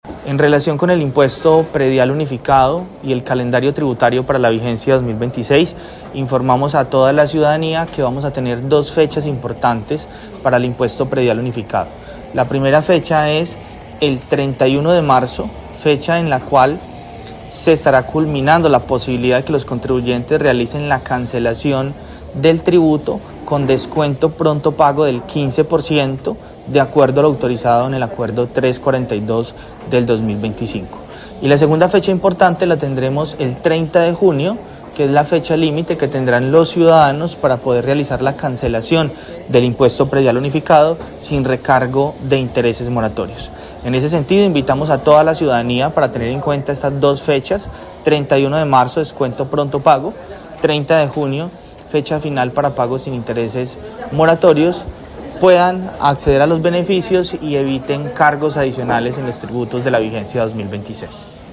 Yeisón Pérez, secretario de hacienda de Armenia
En 6AM/W de Caracol Radio Armenia hablamos con Yeison Andrés Pérez, secretario de hacienda de Armenia que explicó “En relación con el impuesto predial unificado y el calendario tributario para la vigencia 2026, informamos a toda la ciudadanía que vamos a tener dos fechas importantes para el impuesto predial unificado.